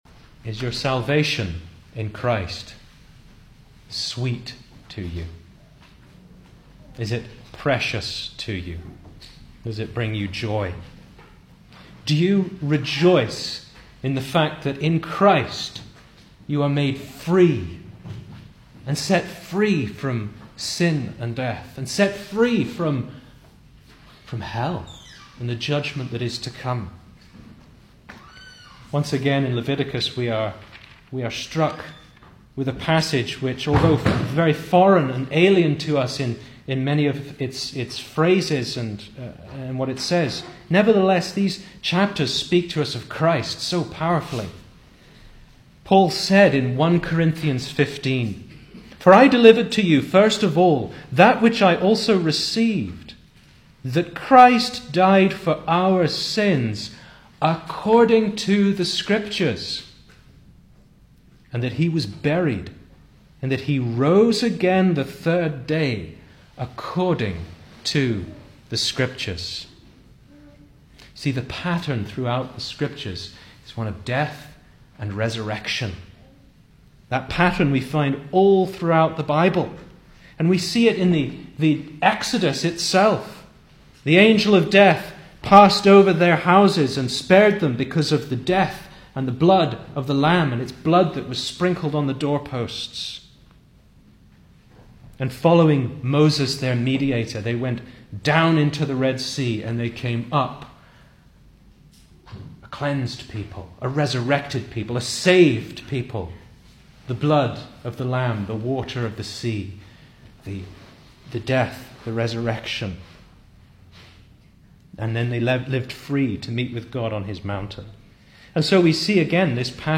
2024 Service Type: Sunday Evening Speaker